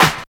51 SNARE 2.wav